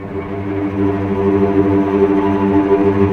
Index of /90_sSampleCDs/Roland - String Master Series/STR_Vcs Tremolo/STR_Vcs Trem p